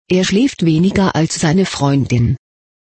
Tragiczna jest wymowa poszczególnych zdań, przykład niżej.
Er schläft weniger als seine Freundin. wypowiedziane jest mniej więcej tak: ihrfflieft weniger bla bla ->